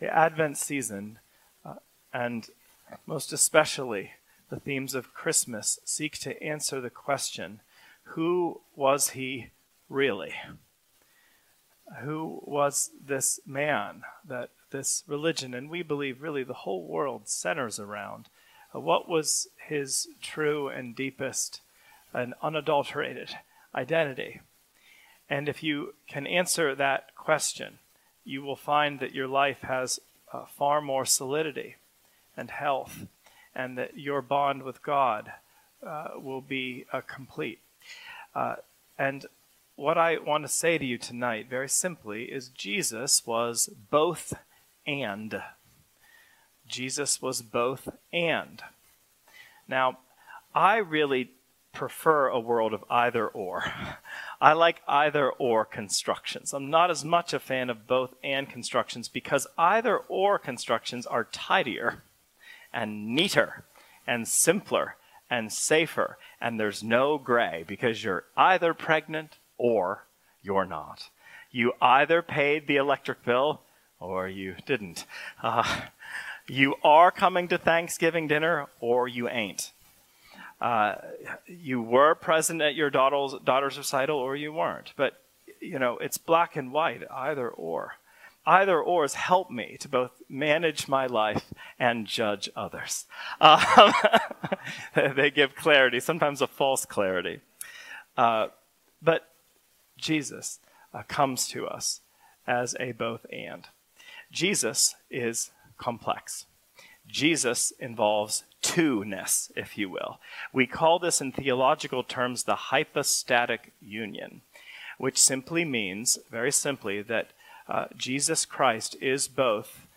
2025 Sermons Really, Though, Who Was He?